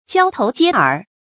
注音：ㄐㄧㄠ ㄊㄡˊ ㄐㄧㄝ ㄦˇ
交頭接耳的讀法